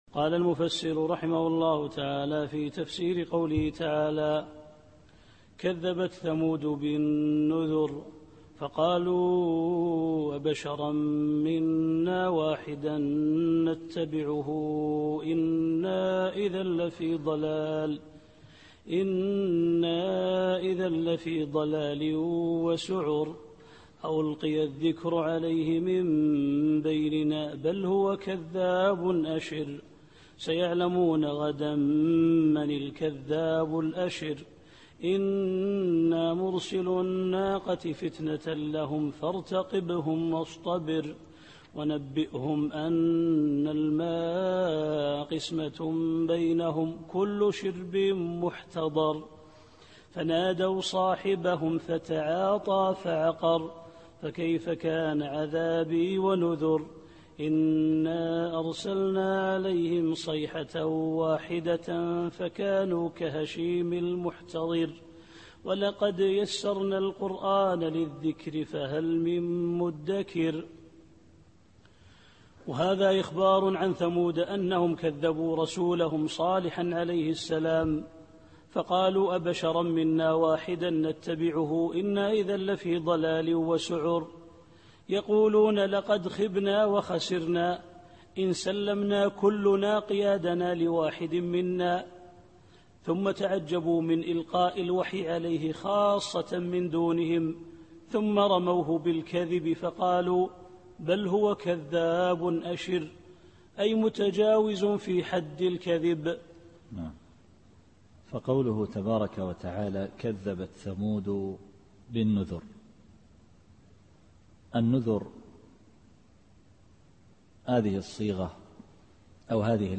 التفسير الصوتي [القمر / 25]